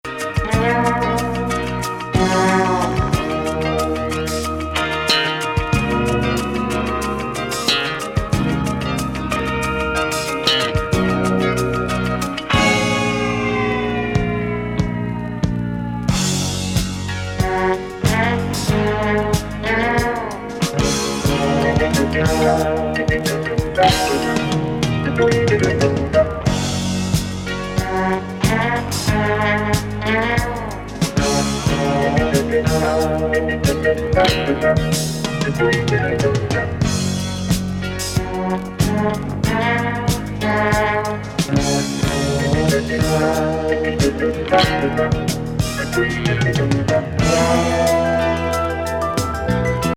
謎ヴォコーダー入りレトロ・フューチャーなレフティ・グルーヴ
ヘビーな